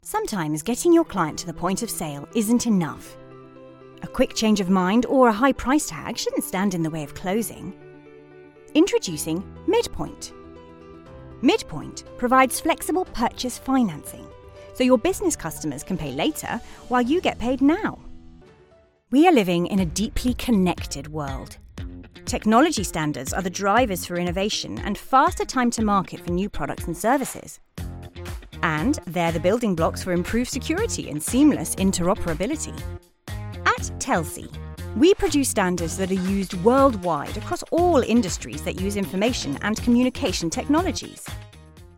English (British)
Explainer Videos
Friendly - Confident - Approachable
Professional & Articulate
Conversational & Relatable
Warm & Emotional
Soothing & Calm
Broadcast quality studio
Rode NT1 mic